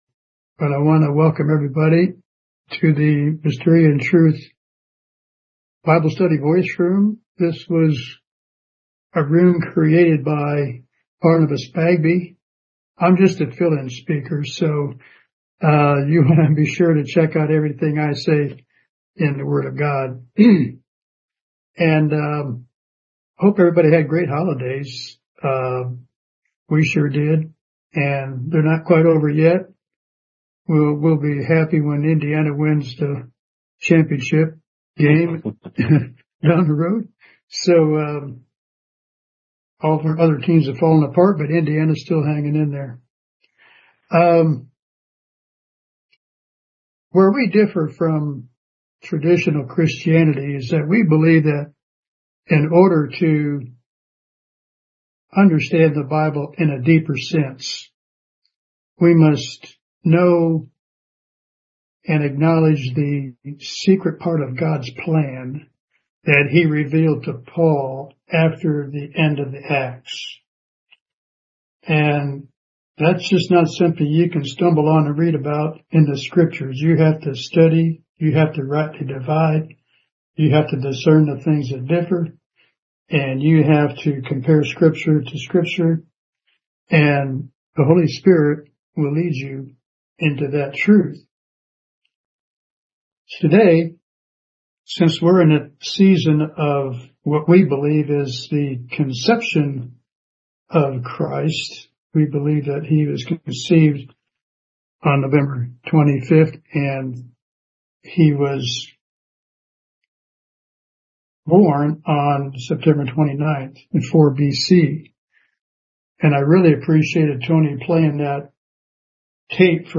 This entry was posted in Bible Study, Topic Studies.